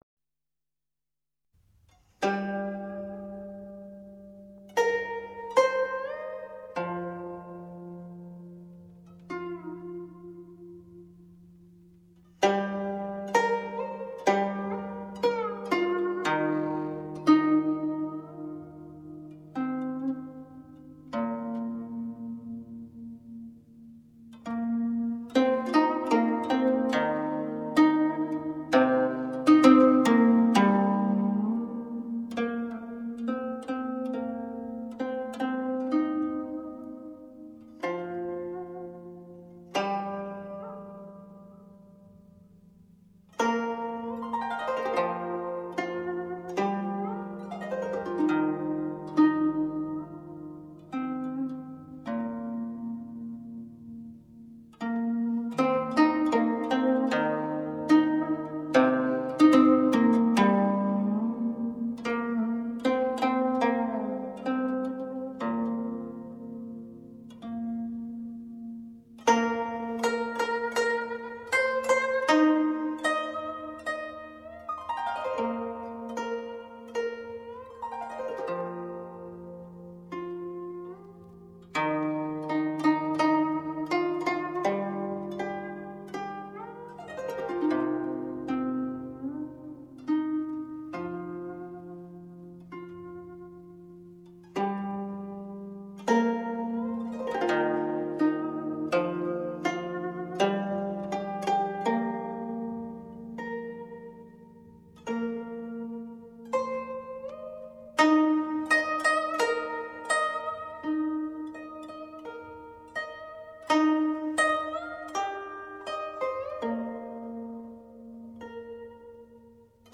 乐器演奏系列
古筝演奏